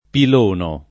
pilonare
pilono [ pil 1 no ]